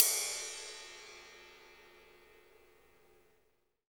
CYM ROCK 04L.wav